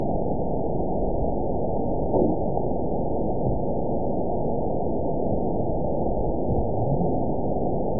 event 920397 date 03/22/24 time 23:59:33 GMT (1 year, 1 month ago) score 7.74 location TSS-AB02 detected by nrw target species NRW annotations +NRW Spectrogram: Frequency (kHz) vs. Time (s) audio not available .wav